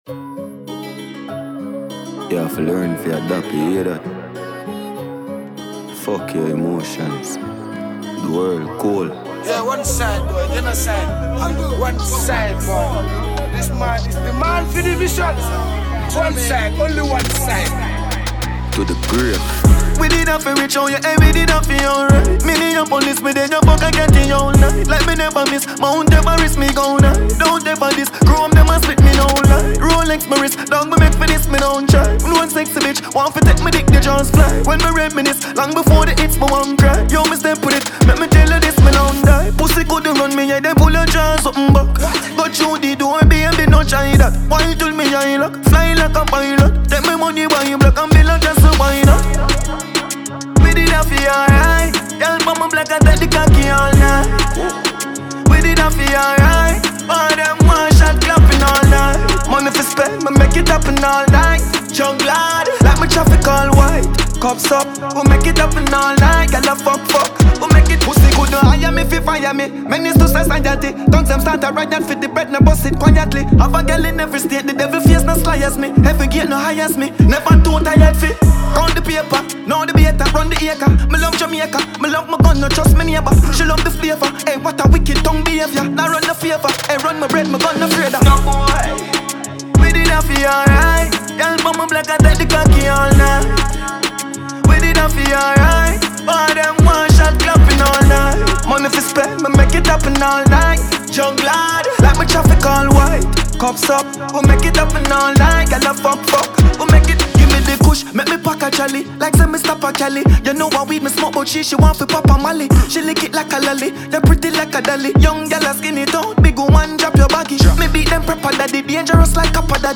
Dancehall
• Genre: Dancehall / Conscious